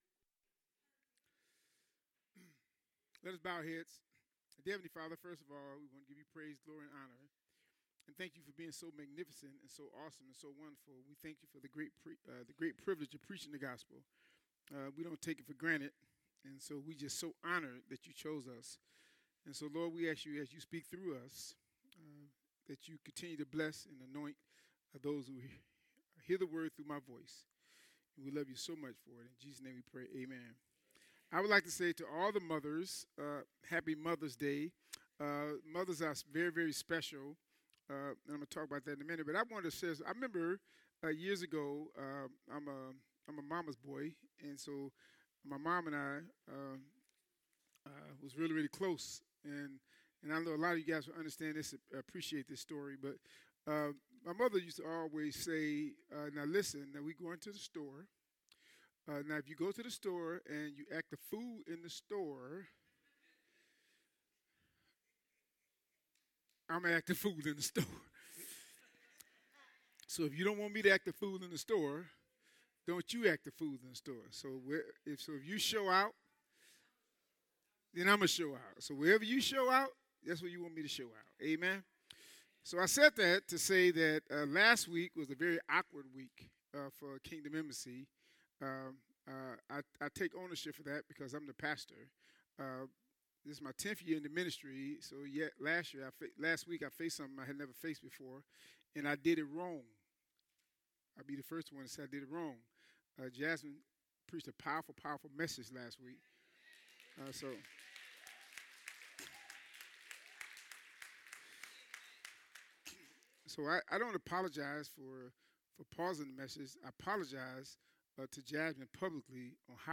Sermons by Kingdom Embassy Covenant Church